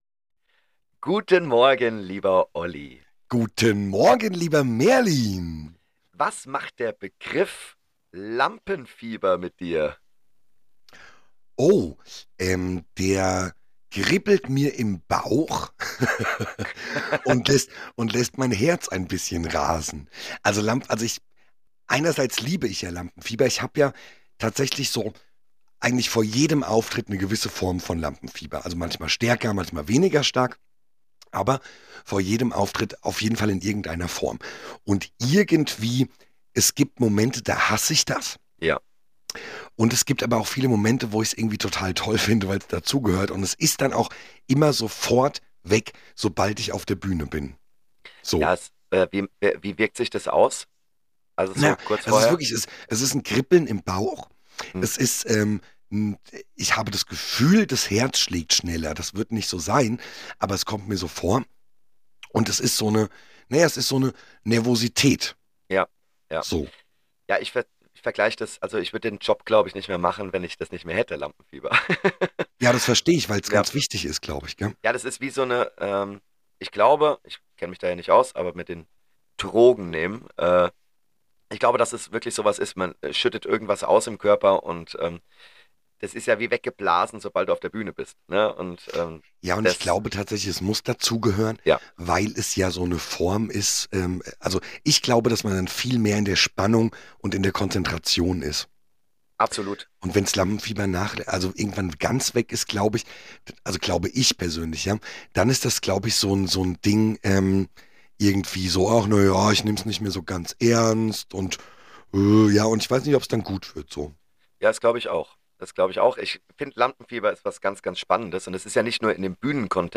Nach einem erfolgreichen gemeinsamen Auftritt plaudern wir über dies und das und jenes. Vom Lampenfieber in die EM-Wochen über CSD und Schulelternbeirat bis hin zu Ferienspielen - ergibt sich ein Shake aus bunten Themen.